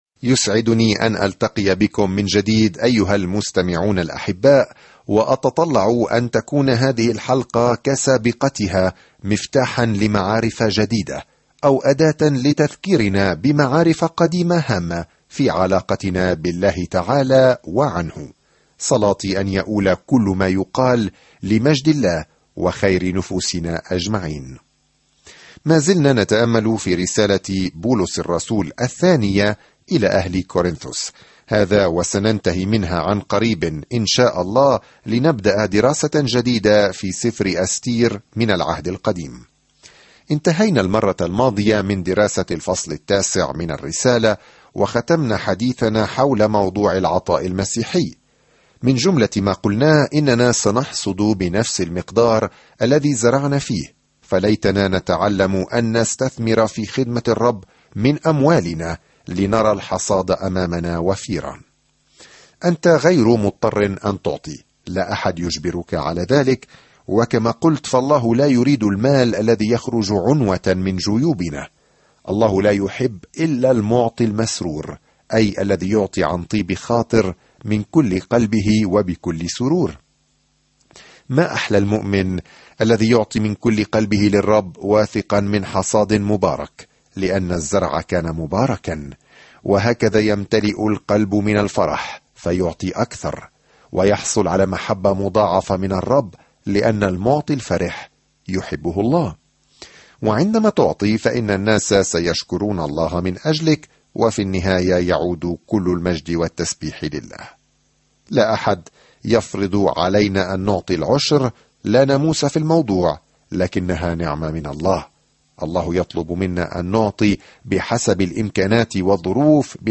سافر يوميًا عبر رسالة كورنثوس الثانية وأنت تستمع إلى الدراسة الصوتية وتقرأ آيات مختارة من كلمة الله.